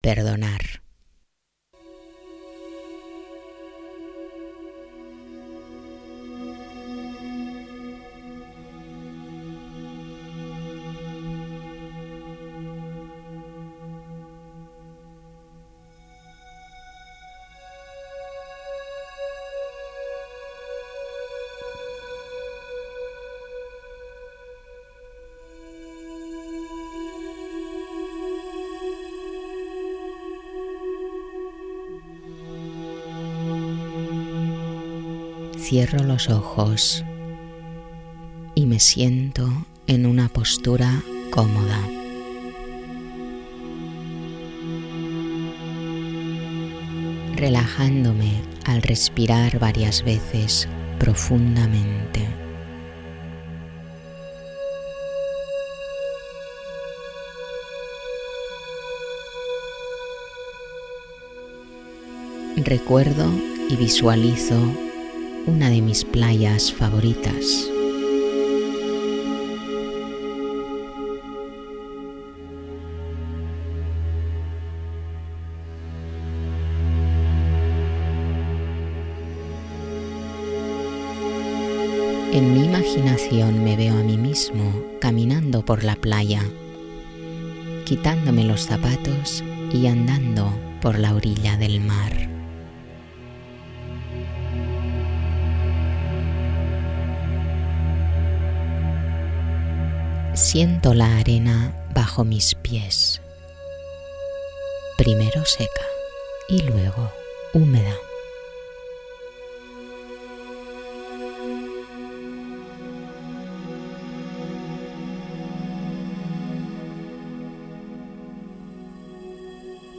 Meditaciones Guiadas